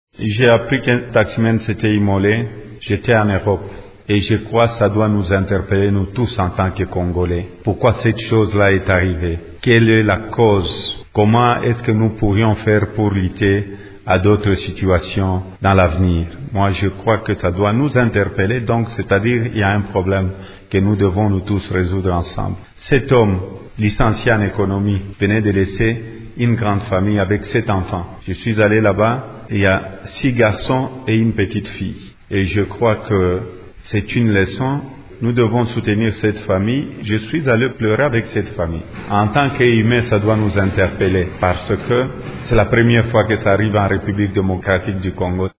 Dans cet extrait sonore, il appelle les bonnes volontés à soutenir cette famille: